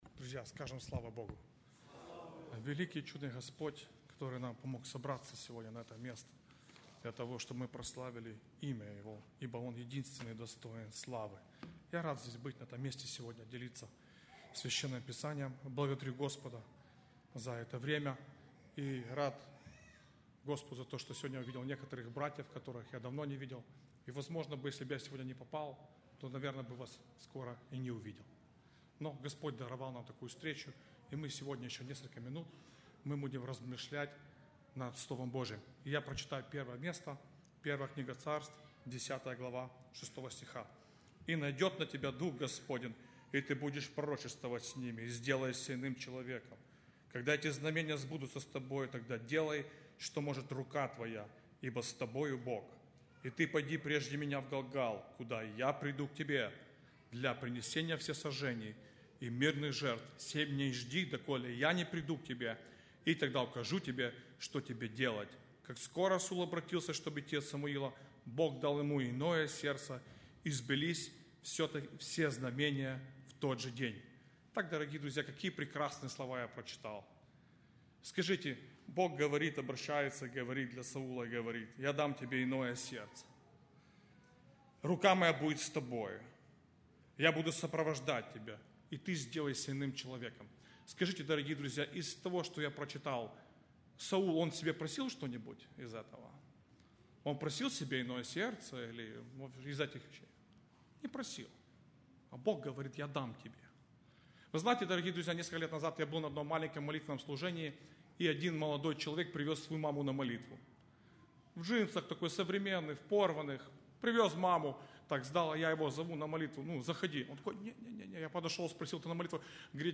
08+Проповедь.mp3